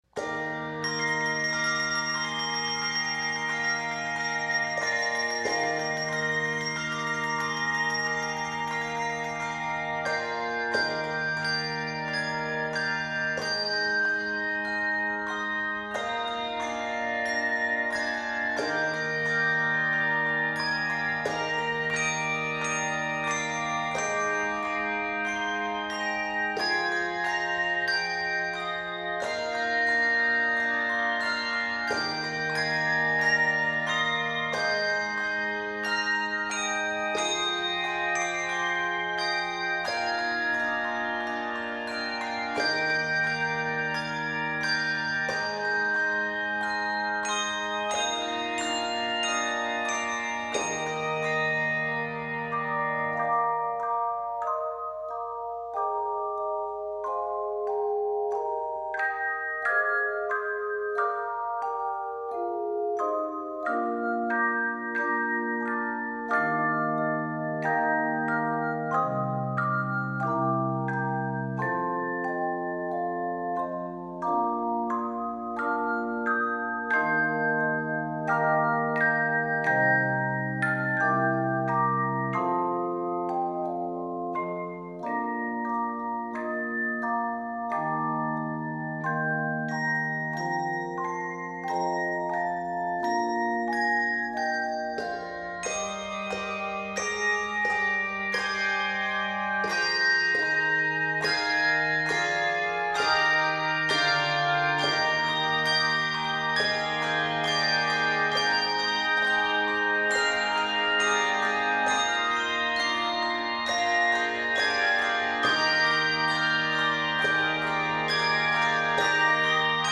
Keys of Eb Major and Bb major.